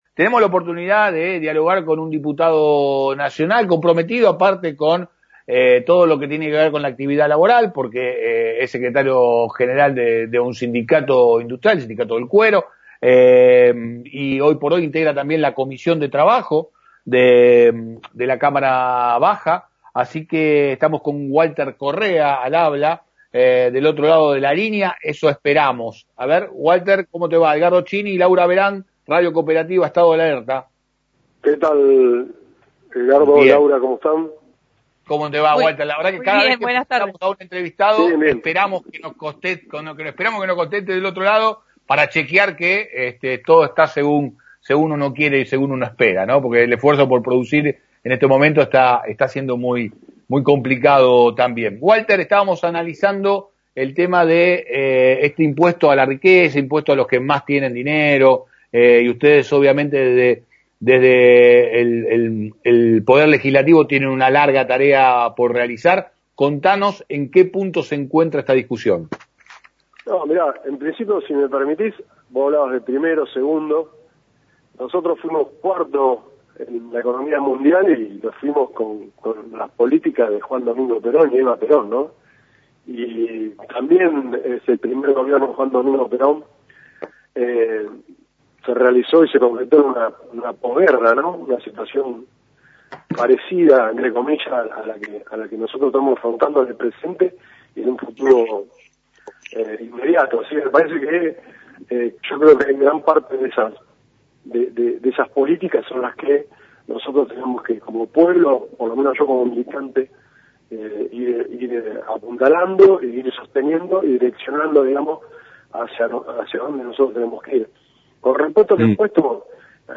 Entrevista a Walter Correa – Sec. Gral. Federación del Cuero (FATICA), del Sindicato de Obreros Curtidores y Diputado Nacional por el Frente de Todos.